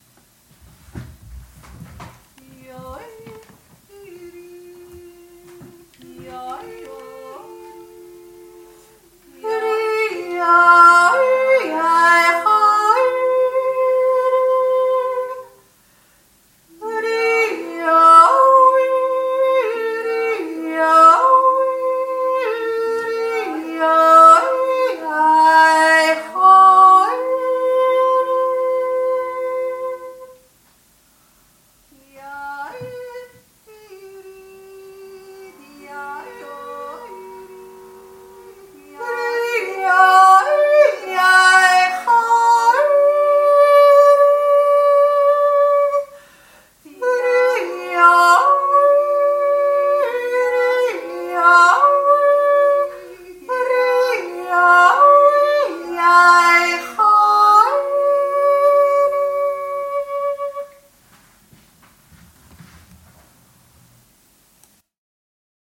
Der Jodler
dritte Stimme
ldner-verkehrte-dritte-stimme.mp3